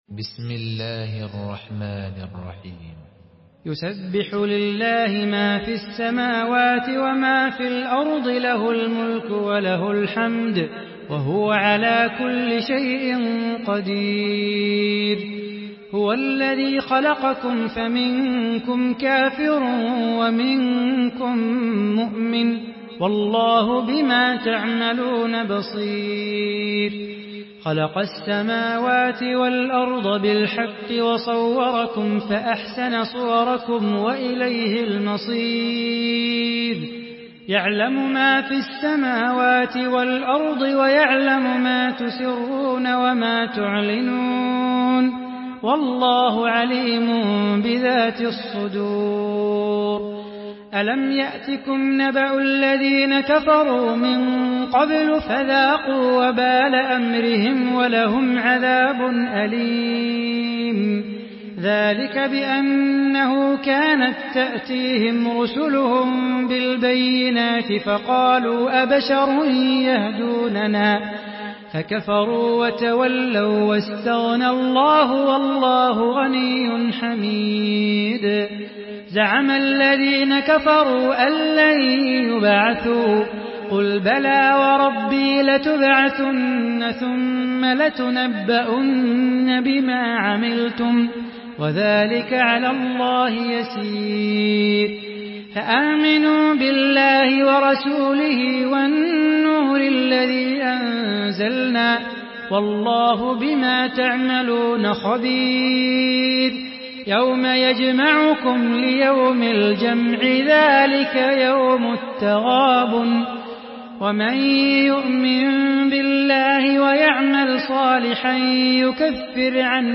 Surah التغابن MP3 in the Voice of صلاح بو خاطر in حفص Narration
Listen and download the full recitation in MP3 format via direct and fast links in multiple qualities to your mobile phone.